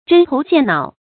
針頭線腦 注音： ㄓㄣ ㄊㄡˊ ㄒㄧㄢˋ ㄣㄠˇ 讀音讀法： 意思解釋： 見「針頭線腦」。